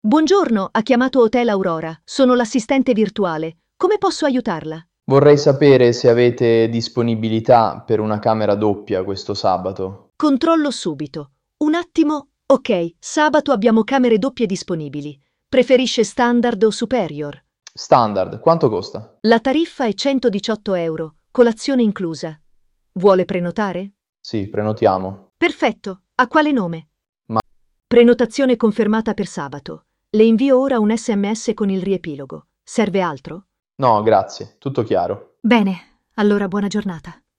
Risponde con voce naturale e tono umano, senza suoni robotici.